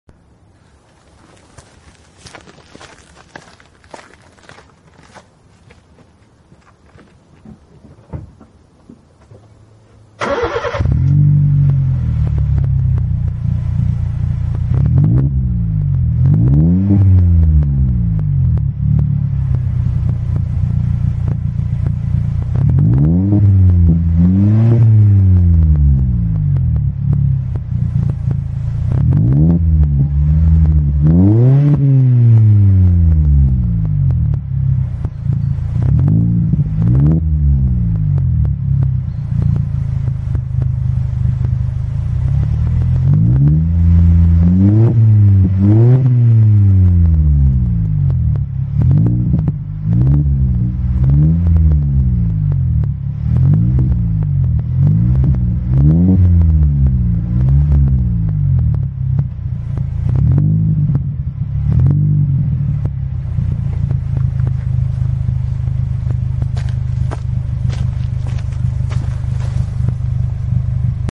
Klang ist sehr angenehm.
Dumpf, gut hörbar aber nicht unangenehm laut.
Dafür, dass der Rest alles Serie ist, klingt das schon sehr schick.
Magnaflow 14821